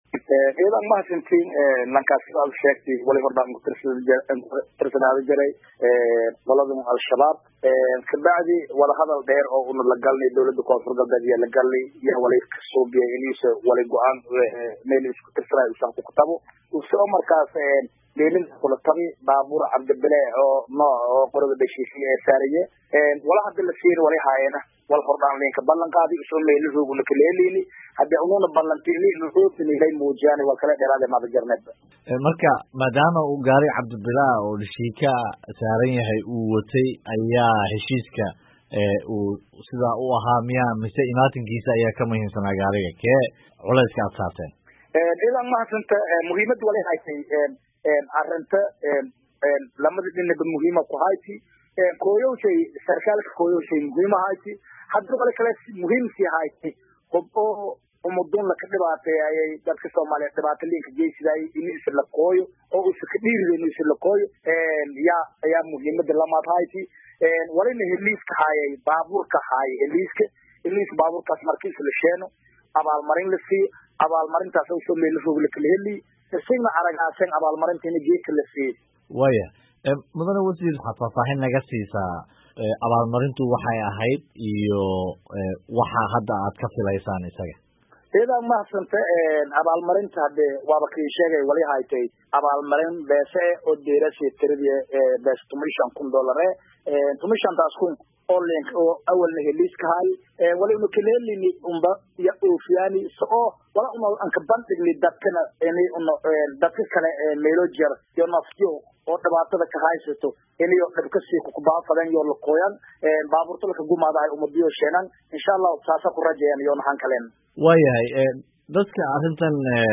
Wasiirka Amniga ee maamaulkaasi, Xasan Maxamed Xuseen, ayaa waraysi uu VOA siiyey ku sharraxay sababaha ay maamulku tallaabadan u qaadeen.